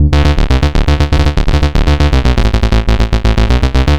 Triplet Waltz D 120.wav